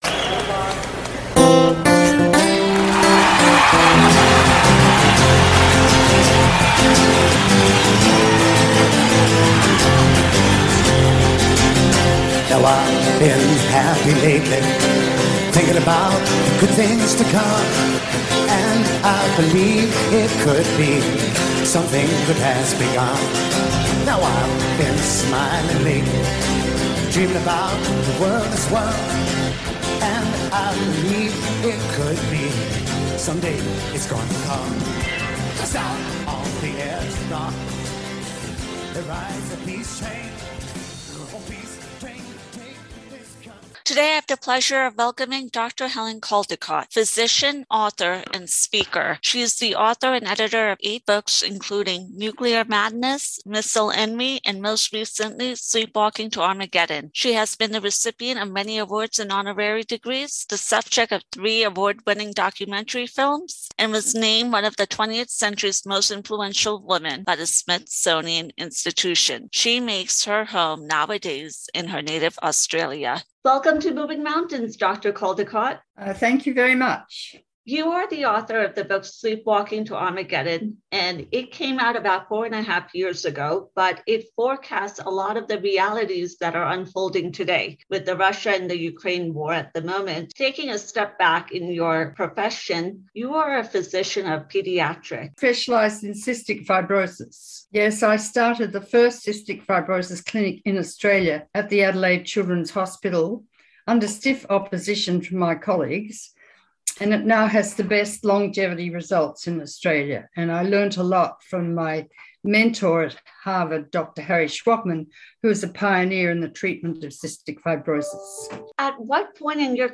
Guest, Dr Helen Caldicott